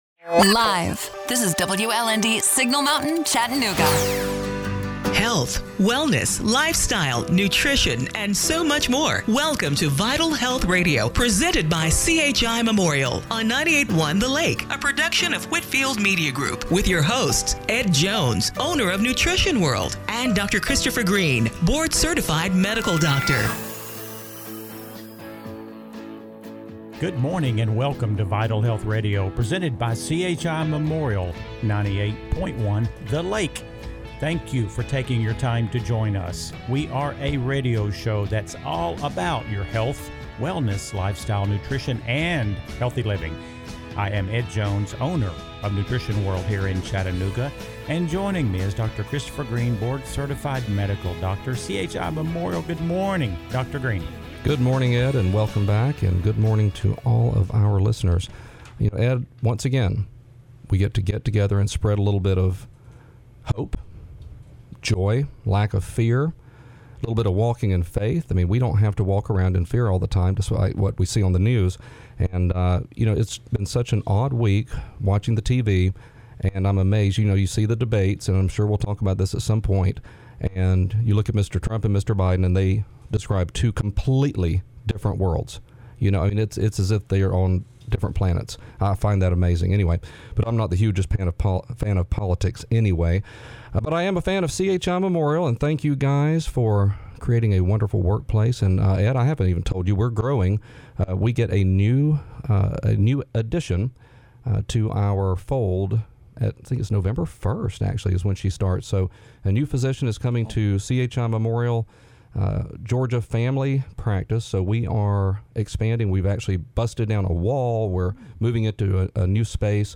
October 18, 2020 – Radio Show - Vital Health Radio